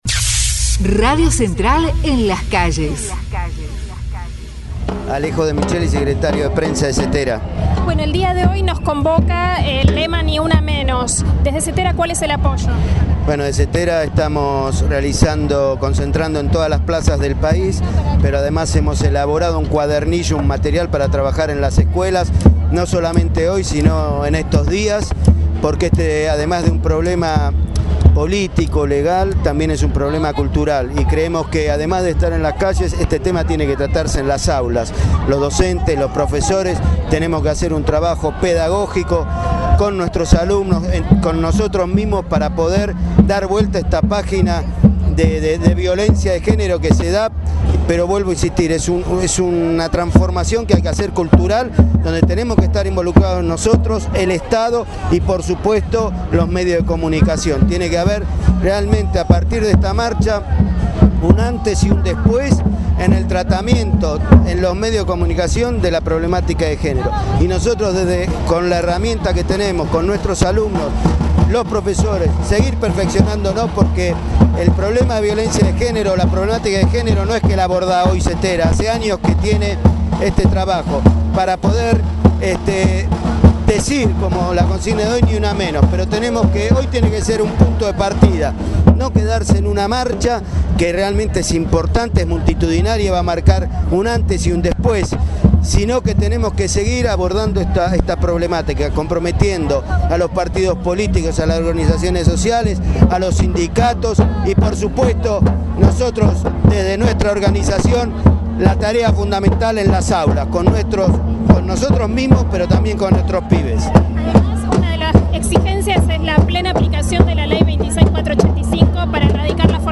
cobertura especial de RADIO CENTRAL